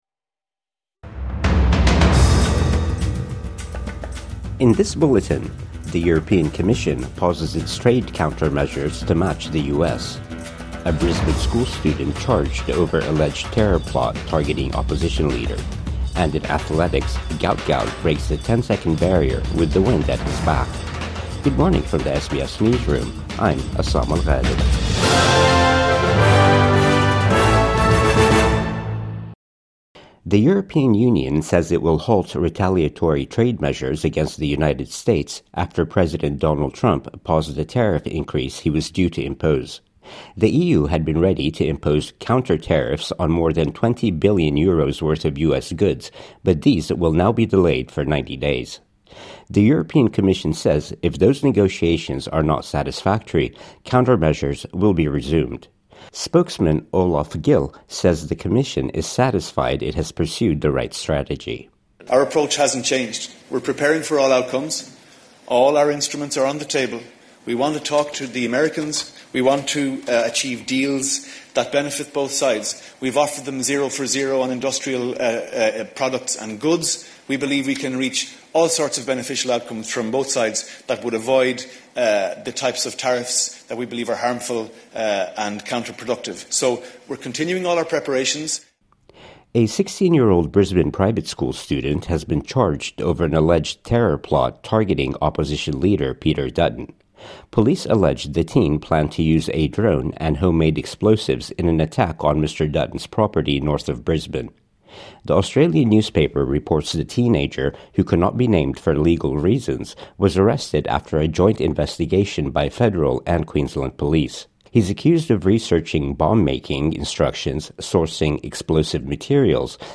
16-year-old charged over alleged plot targeting Peter Dutton | Morning News Bulletin 11 April 2025